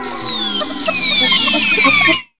5804L SYN-FX.wav